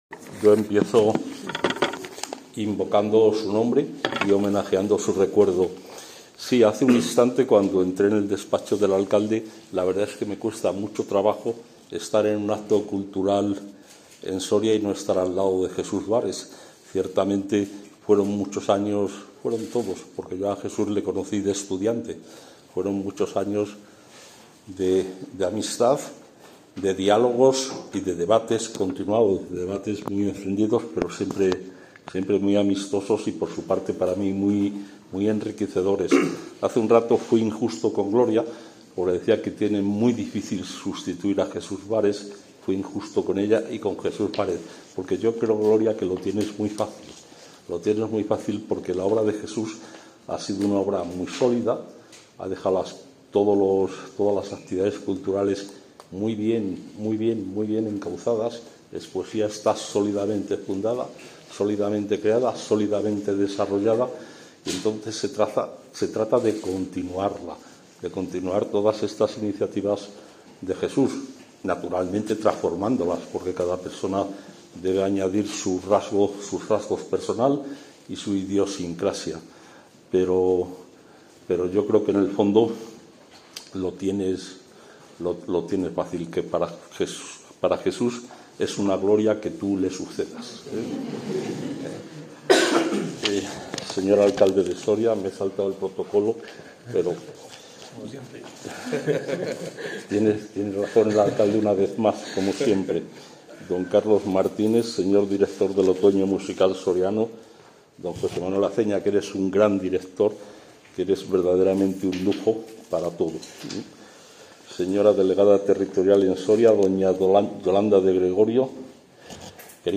Declaraciones de Gonzalo Santonja.mp3